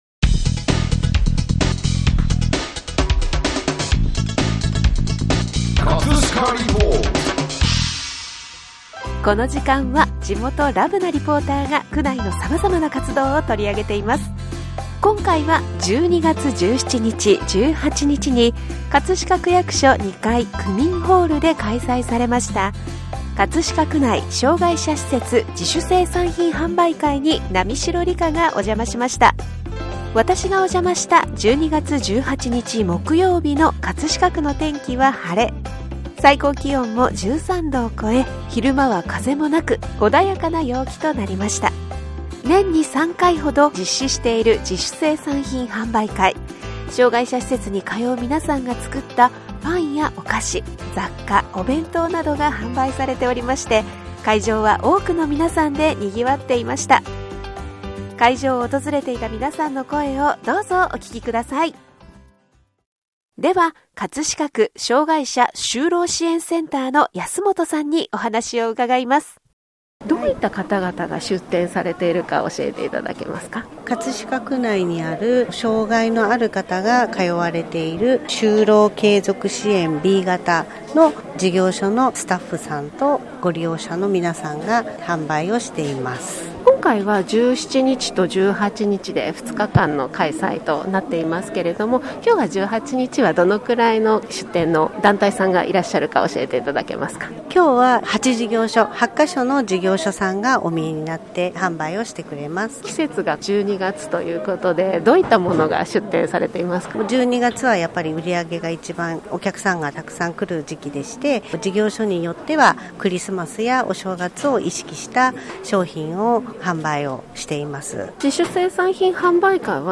【葛飾リポート】 葛飾リポートでは、区内の様々な活動を取り上げています。
障害者施設に通う皆さんが作ったパンやお菓子、雑貨、お弁当などが販売されており、会場は多くの皆さんでにぎわっていました。 <Commemoi コムモア＞ ＜東堀切くすのき園＞ ＜パン工房 ル・マンマ＞ ＜生活介護事業所シャングリラ＞ ＜手まり＞ ＜白鳥福祉館＞ ＜かがやき夢工場＞ ＜パランしょうぶ＞ ＜次回の障害者施設自主生産品販売会 予定＞ 日時：令和８年 ２月４日㈬～５日㈭ 午前11時から午後2時 場所：葛飾区役所２階区民ホール 会場を訪れていた皆さんの声をどうぞお聴きください。